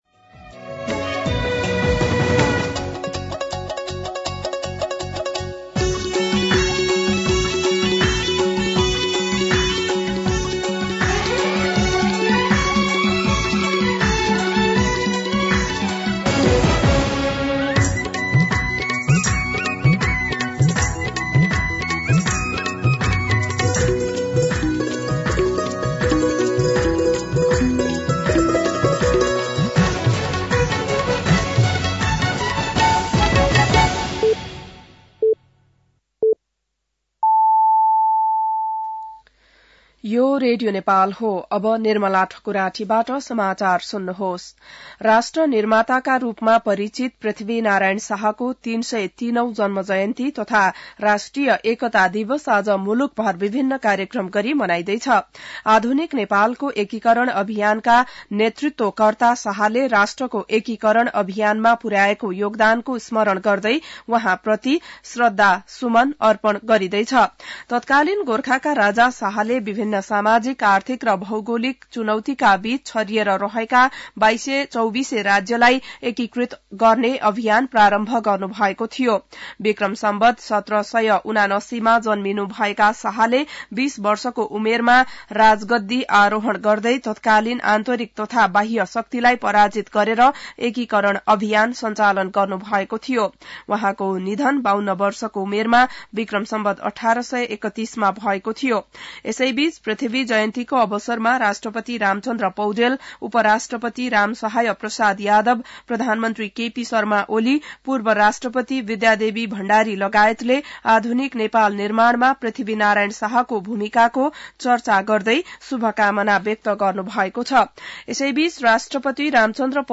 An online outlet of Nepal's national radio broadcaster
बिहान ११ बजेको नेपाली समाचार : २८ पुष , २०८१